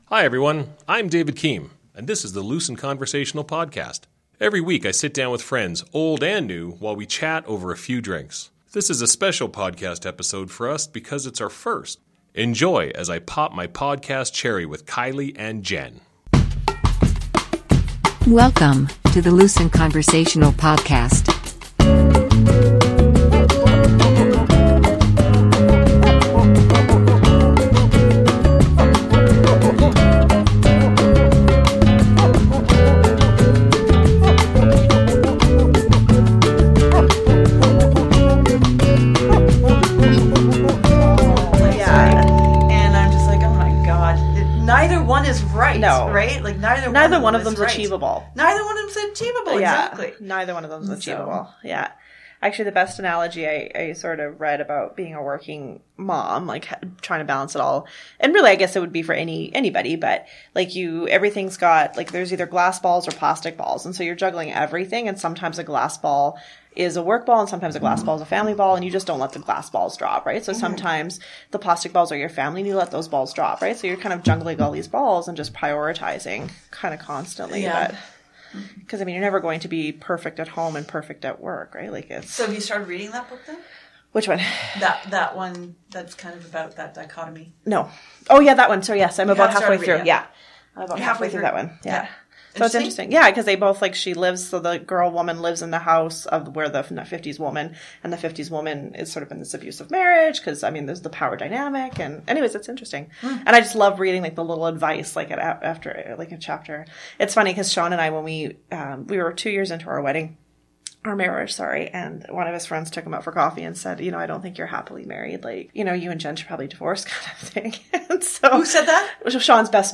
This episode has us getting used to microphones and flying by the seat of our pants.